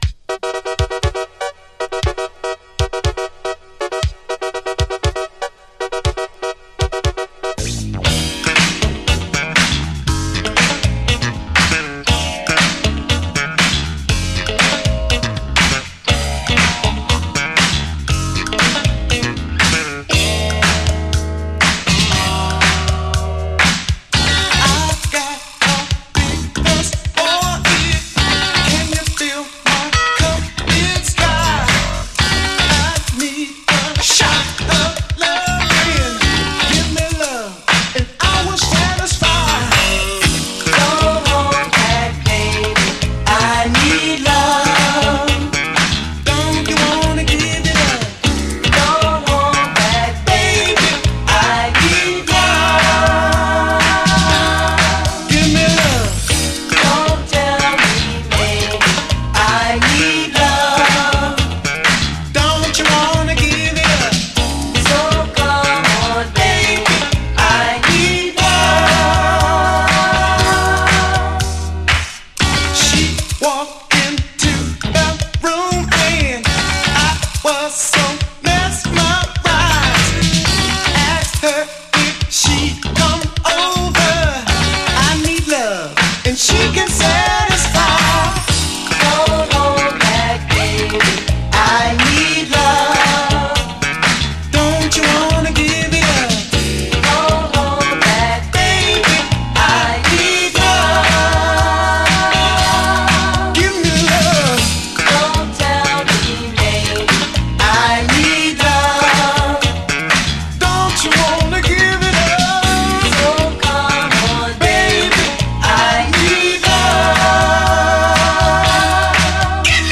SOUL, 70's～ SOUL, DISCO
ギラギラの80’Sブギー・モダン・ファンク！
強靭なチョッパー・ベースが唸る！